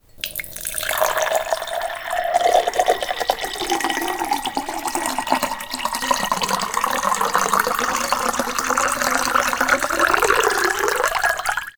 Tiếng rót nước vào cốc MP3